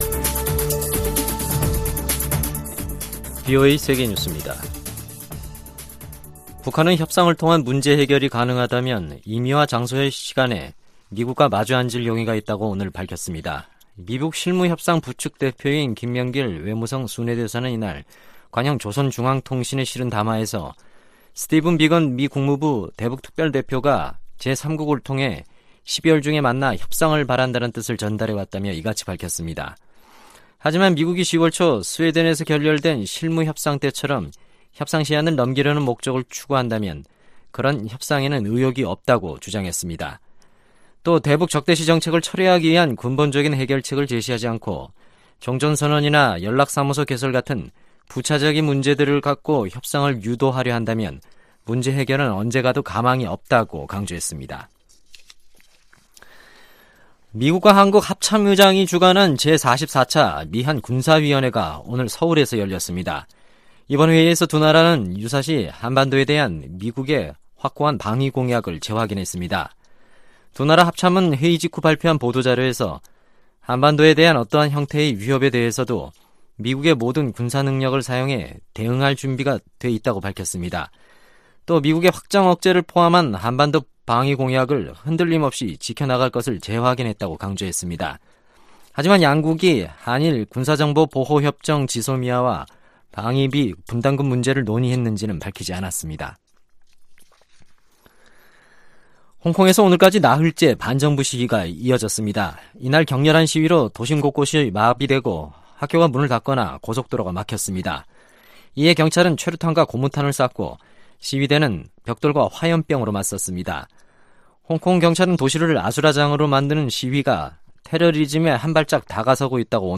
VOA 한국어 간판 뉴스 프로그램 '뉴스 투데이', 2019년 11월 14일 3부 방송입니다. 마크 에스퍼 미 국방장관이 북한과의 비핵화 협상을 위한 미-한 연합군사훈련의 변경 가능성을 밝혔습니다. 미국 상원 의원들은 북한이 ‘연말 시한’을 강조하며 대미 압박의 강도를 높이고 있는 데 대해 강하게 비난하며 제재 강화를 주장했습니다.